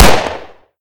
glock.ogg